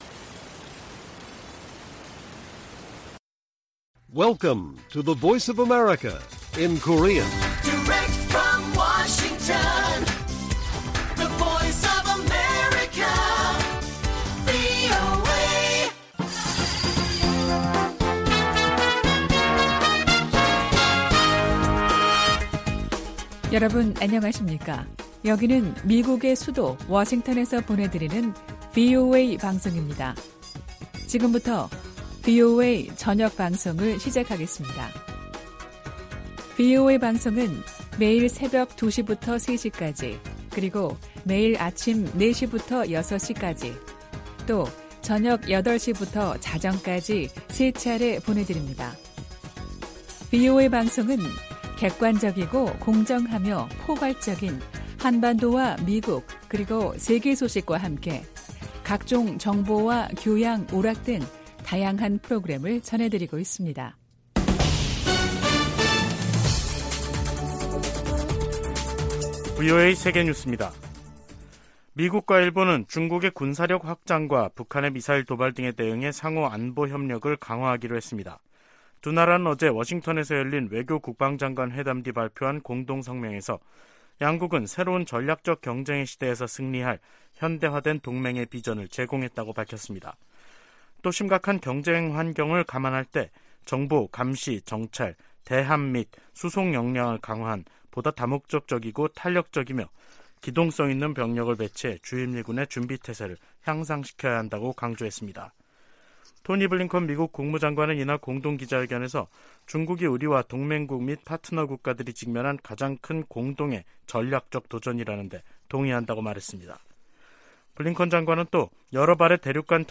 VOA 한국어 간판 뉴스 프로그램 '뉴스 투데이', 2023년 1월 12일 1부 방송입니다. 윤석열 한국 대통령이 핵무기 개발 등 북한의 도발과 안보 위협에 대응한 자체 핵 무장 가능성을 언급했습니다. 미국과 일본의 외교・국방 장관이 워싱턴에서 회담을 갖고 북한의 탄도미사일 도발 등에 대응해 미한일 3자 협력을 강화하기로 거듭 확인했습니다.